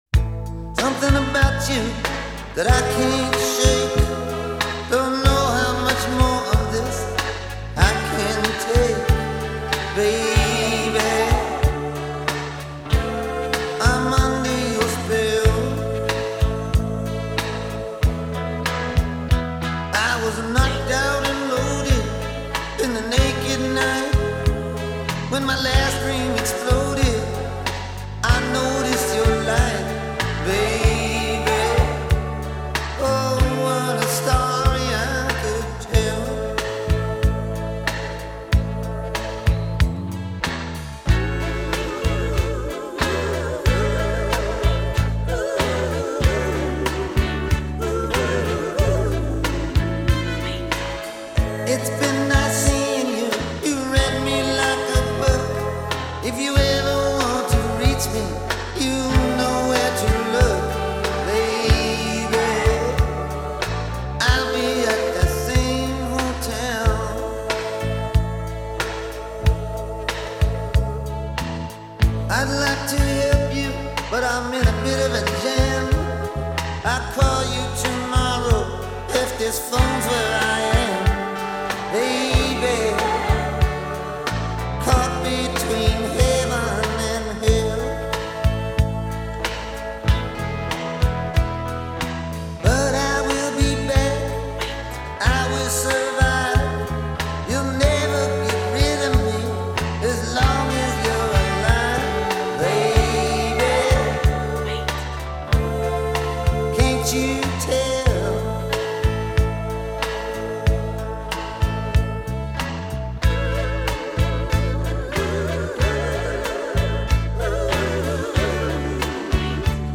a breezy heartbreak tune